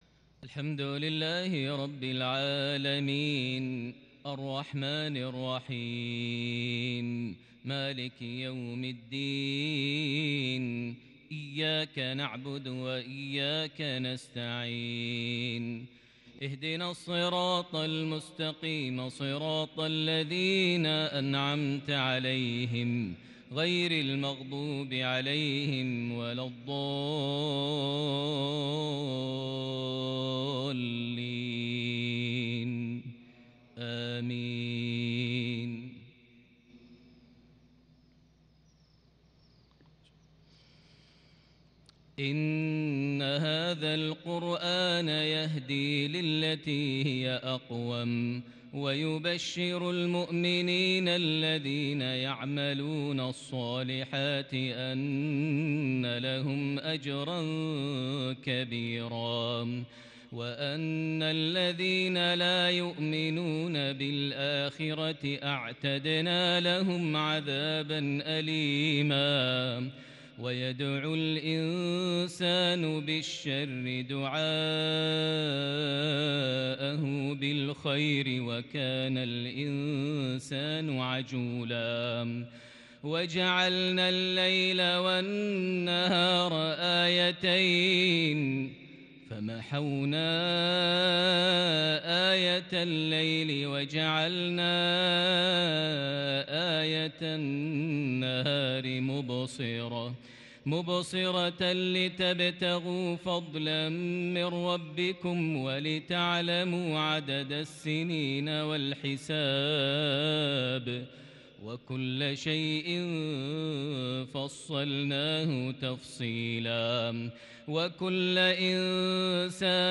تلاوة شجية للغاية من سورة الإسراء (9-21) | مغرب 19 صفر 1442هـ > 1442 هـ > الفروض - تلاوات ماهر المعيقلي